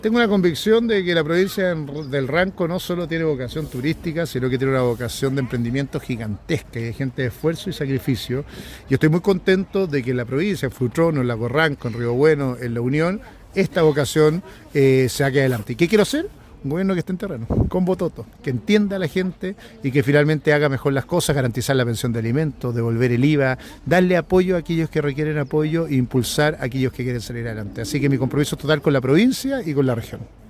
Una de las comunas escogidas fue Río Bueno, donde participó en un encuentro con vecinos de la comuna y se reunió con candidatos a senadores y diputados, así como también, con alcaldes de la provincia del Ranco.
CUÑA-1-SICHEL-RANCO.mp3